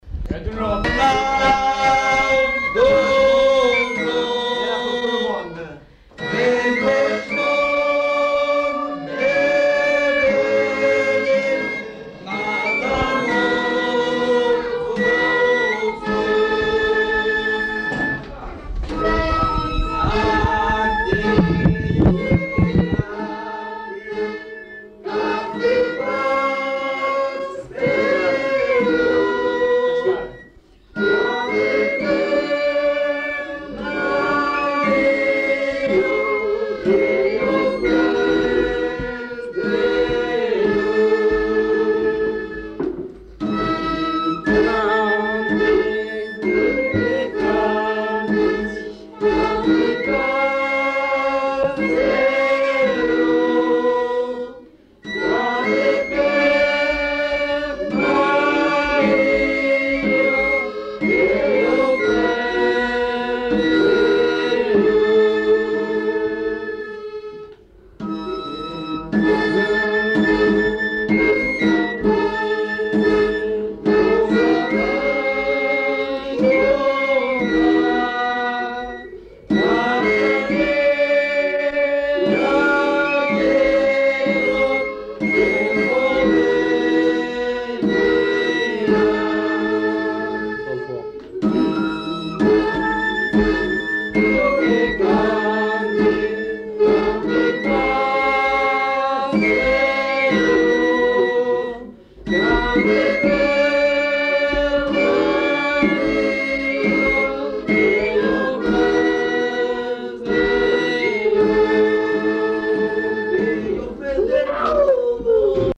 Aire culturelle : Béarn
Lieu : Castet
Genre : chanson-musique
Type de voix : voix mixtes
Production du son : chanté
Instrument de musique : flûte à trois trous ; tambourin à cordes ; accordéon diatonique